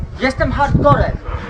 Worms speechbanks
KAMIKAZE.wav